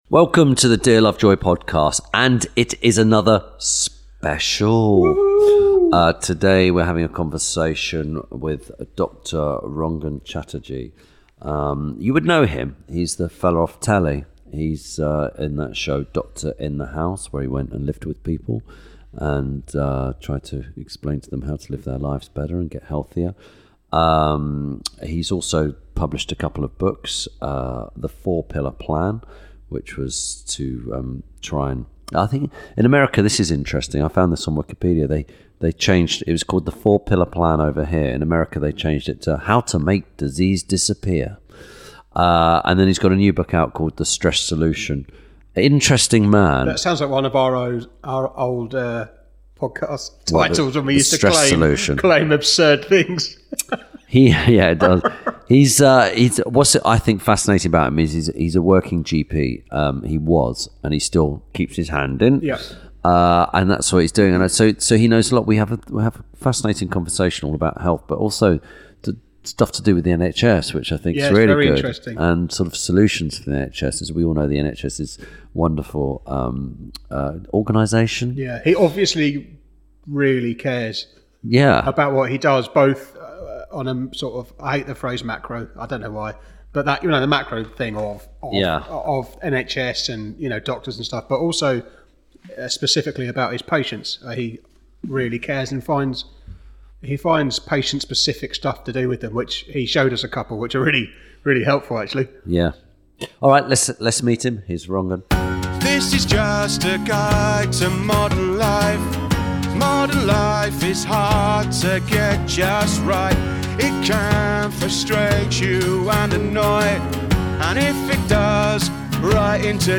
Ep. 108 – RANGAN CHATTERJEE - A Conversation With… – INTERVIEW SPECIAL
This week Tim Lovejoy talks to physician, author and television presenter Rangan Chatterjee. They discuss the personal story that made him approach functional medicine, his new book The Stress Solution and the huge impact stress has on our lives.